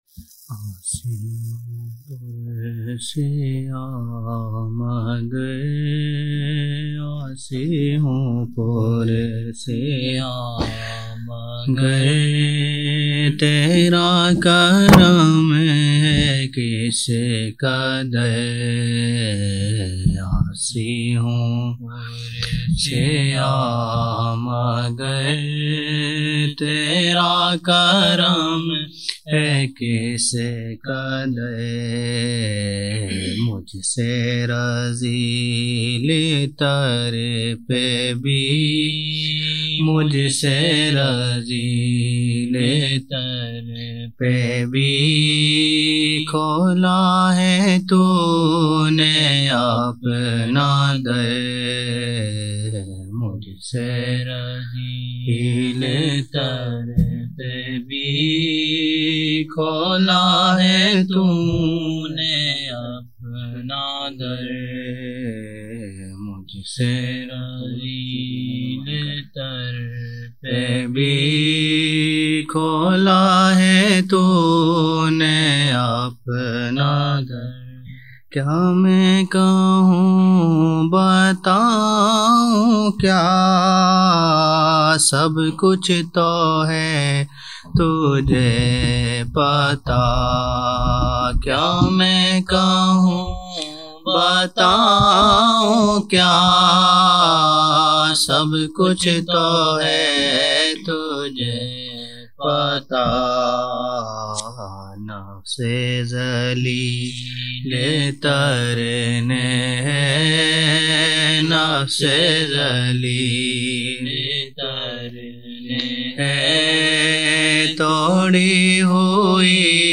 24 November 1999 - Isha mehfil (16 Shaban 1420)
Naat shareef:
Chand naatia ashaar